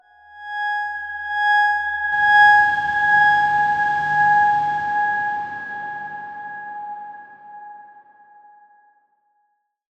X_Darkswarm-G#5-pp.wav